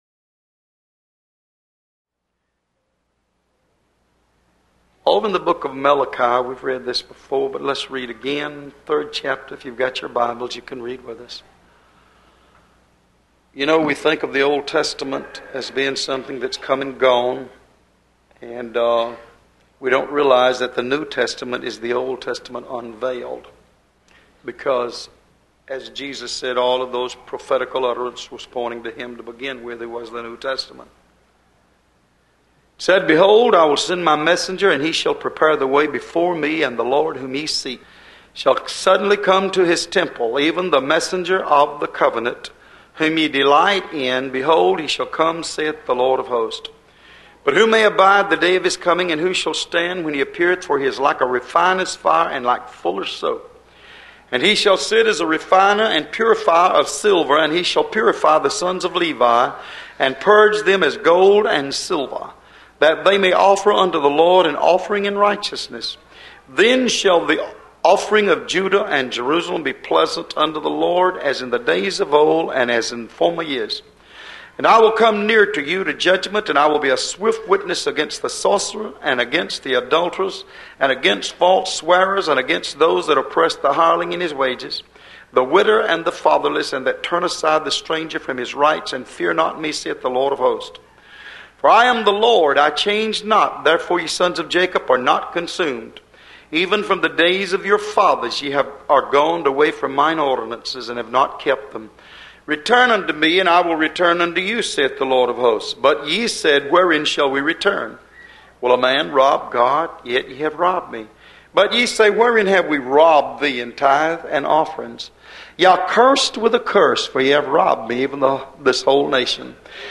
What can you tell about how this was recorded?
Location: Love’s Temple in Monroe, GA USA